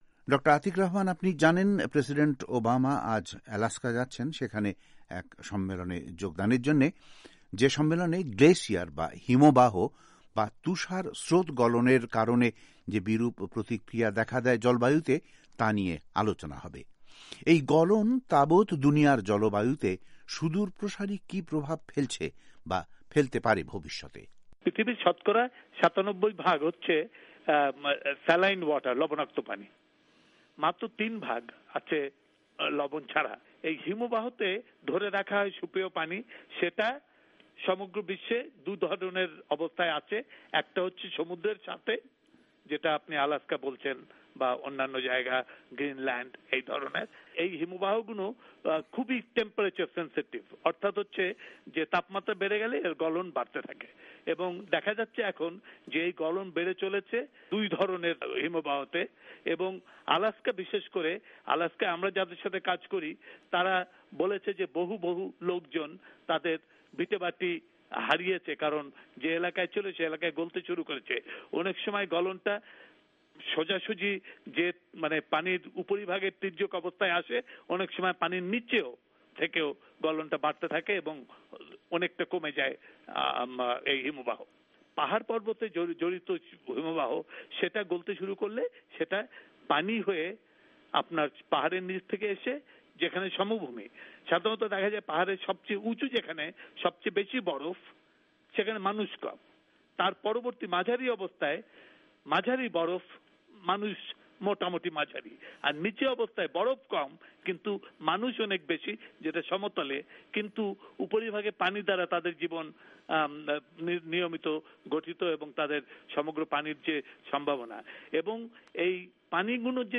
INTV